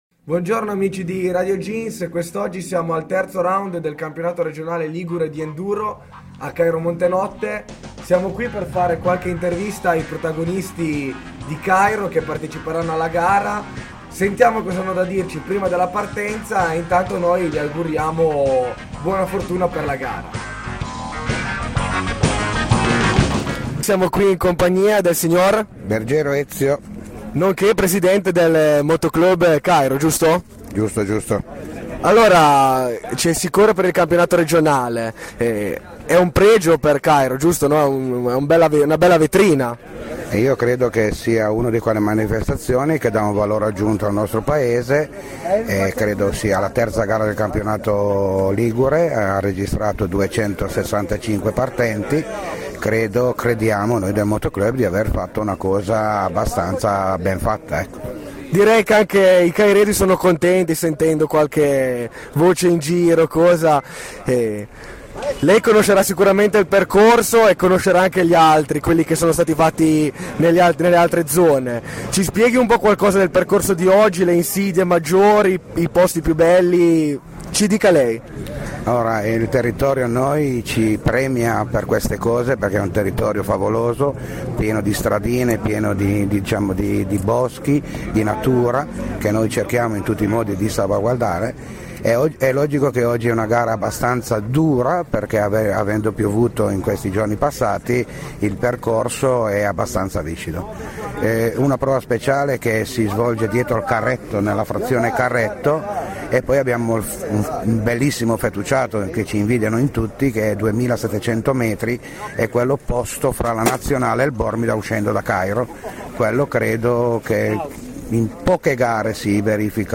Intervista a CAMPIONATO REGIONALE DI MOTOCROSS 2011